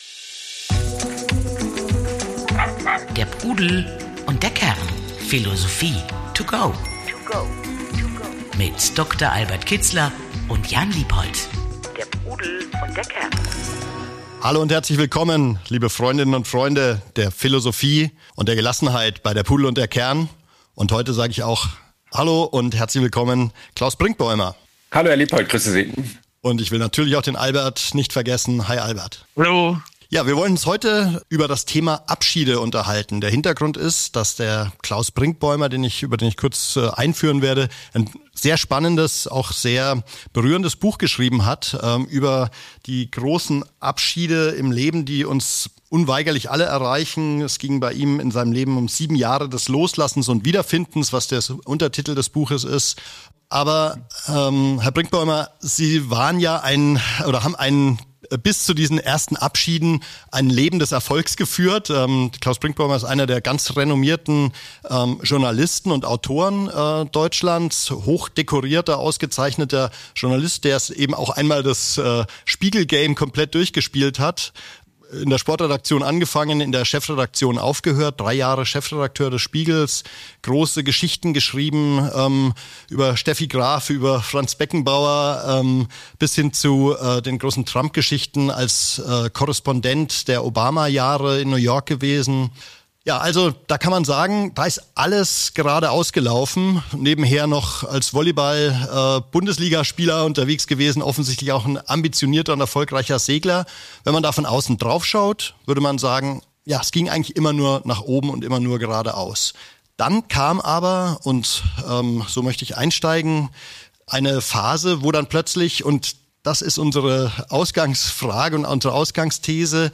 Interview mit dem Journalisten und Bestsellerautor Klaus Brinkbäumer. ~ Der Pudel und der Kern - Philosophie to go Podcast